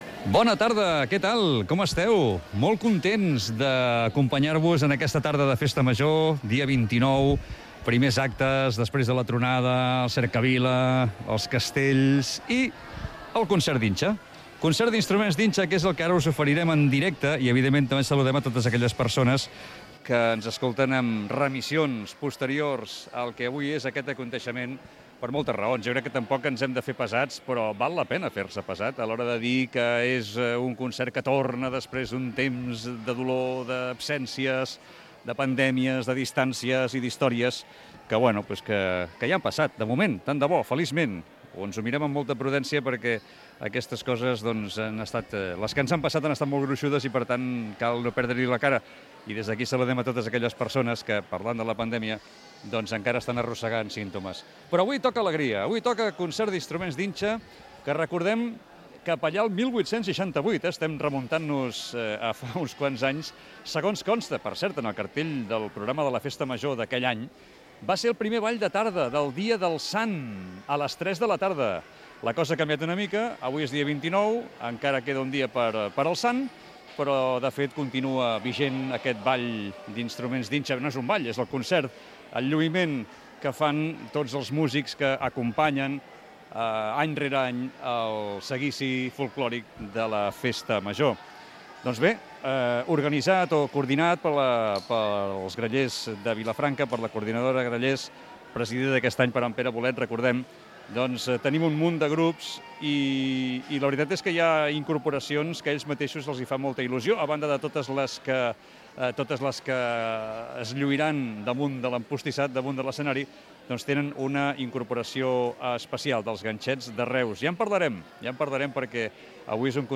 Els actes de la Festa Major de Vilafranca del Penedès.
Concert d'inxa